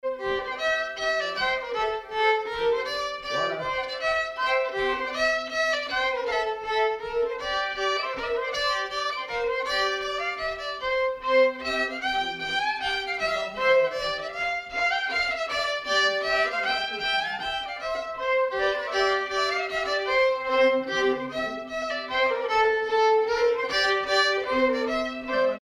Danse
Villard-sur-Doron
danse : mazurka
circonstance : bal, dancerie
Pièce musicale inédite